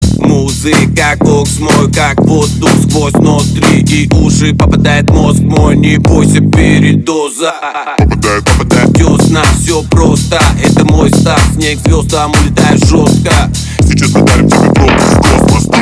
• Качество: 321, Stereo
мужской голос
жесткие
мощные басы
Жанр: G-house